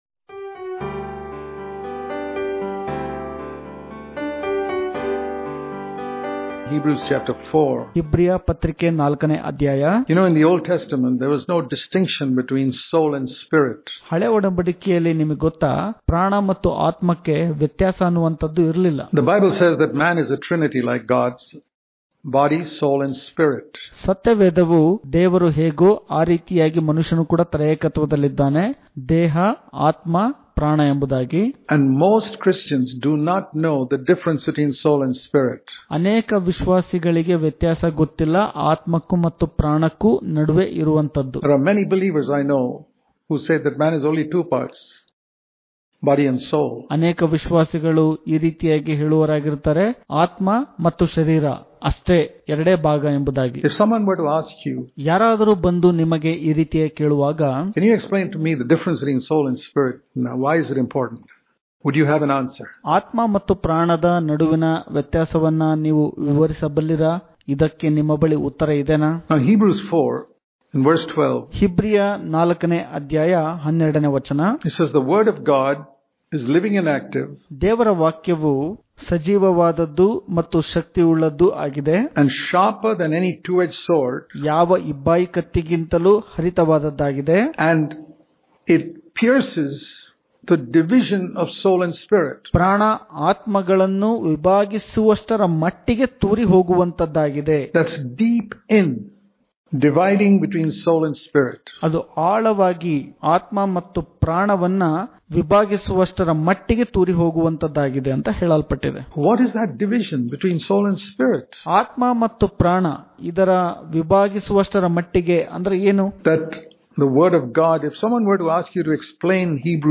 June 30 | Kannada Daily Devotion | Dying To Our Own Will Daily Devotions